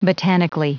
Prononciation du mot botanically en anglais (fichier audio)
Prononciation du mot : botanically